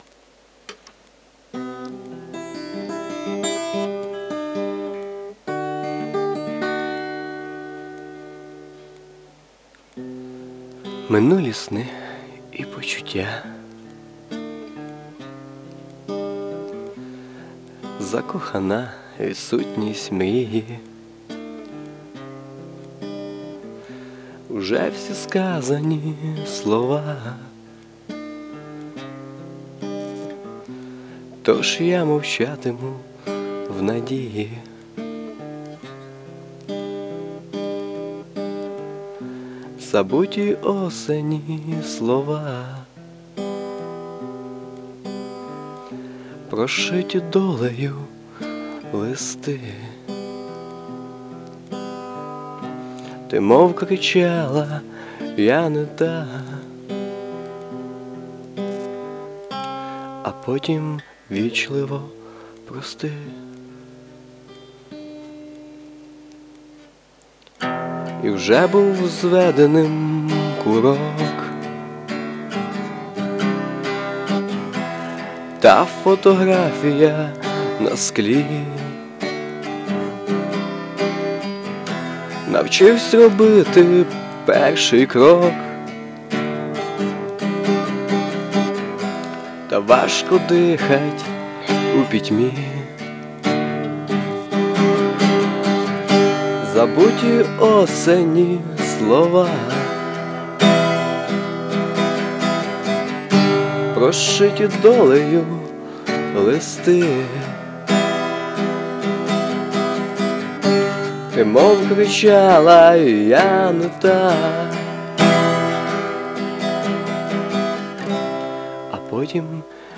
Вашим голосом співати рок-балади. wink Гарно! give_rose